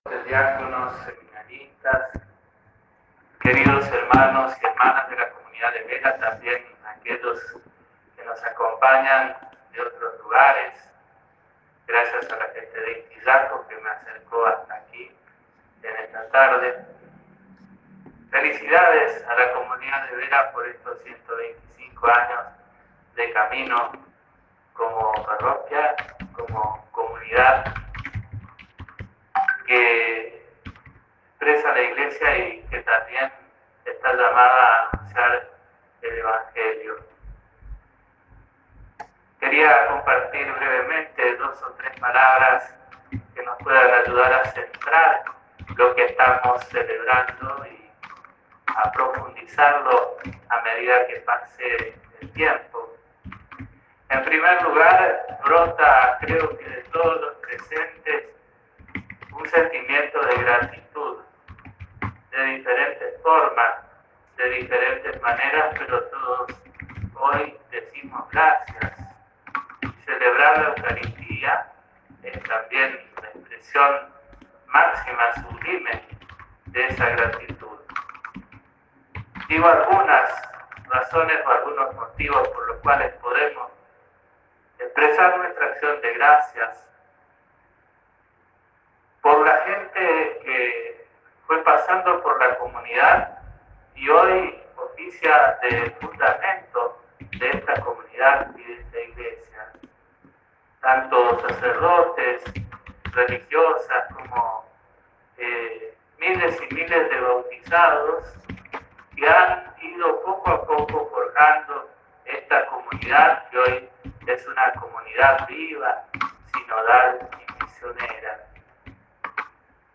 En su homilía, el prelado expresó la gratitud hacia la comunidad de Vera por su trabajo en la Iglesia, la importancia de la contemplación en la Celebración de la Eucaristía y la necesidad de construir y cuidar la Iglesia como comunidad en lugar de enfocarse únicamente en el templo físico. También subrayó la importancia de acoger y ayudar a los más necesitados.